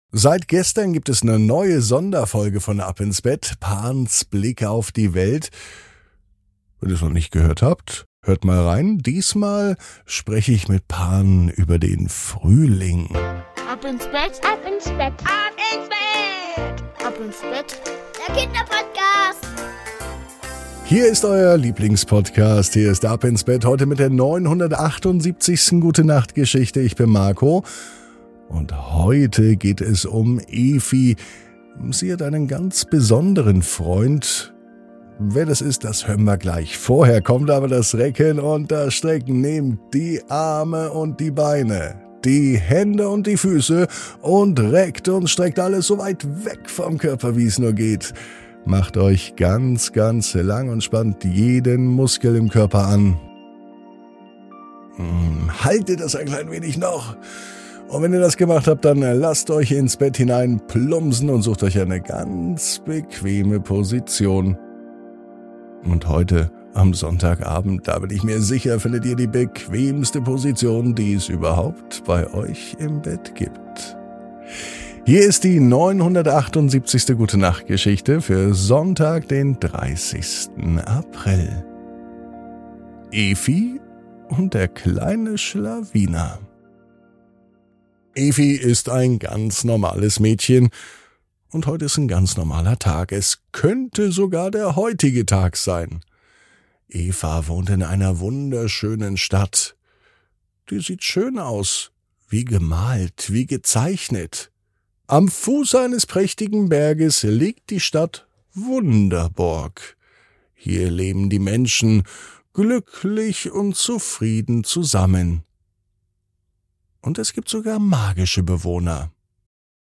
#978 Evi und der kleine Schlawiner ~ Ab ins Bett - Die tägliche Gute-Nacht-Geschichte Podcast